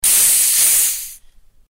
Звуки газировки
Шипение газа при открытии газировки